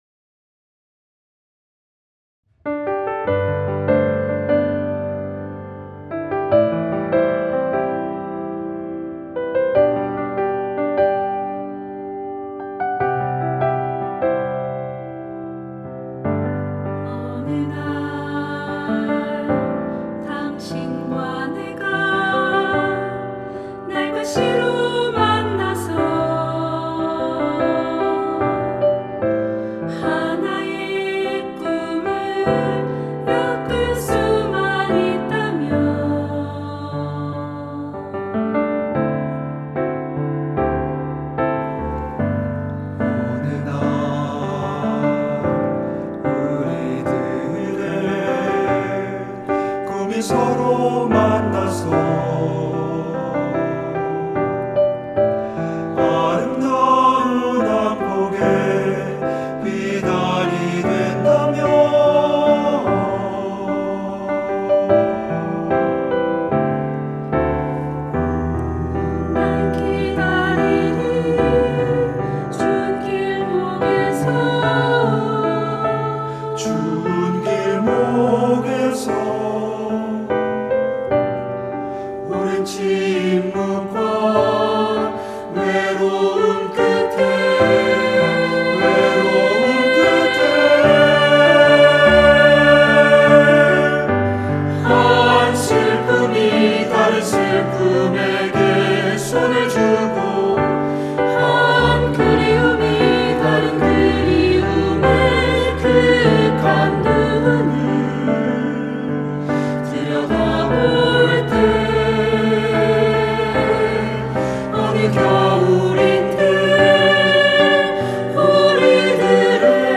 304명시민합창_전체버전.mp3
참사 희생자 304명을 기리는 의미로 304명의 시민 합창단원을 일시적으로 모집하여 두 번의 합창 연습을 했습니다.